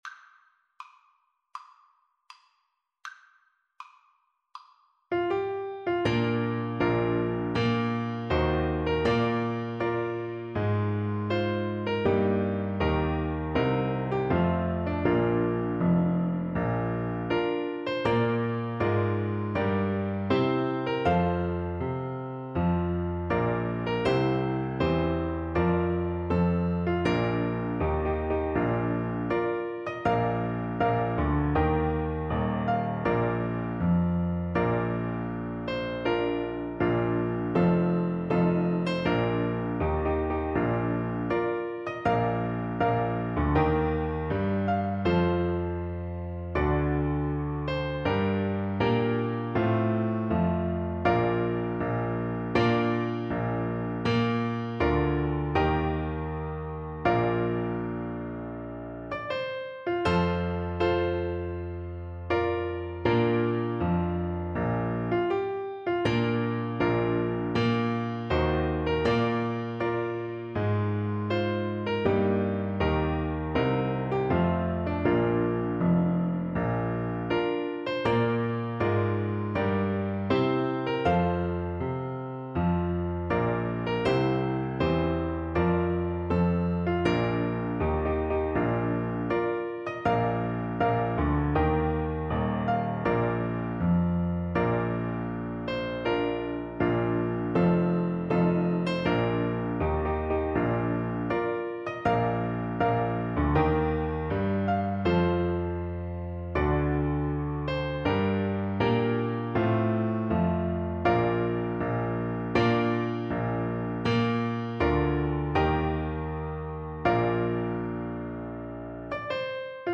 4/4 (View more 4/4 Music)
Andante maestoso =80
D5-G6
National Anthems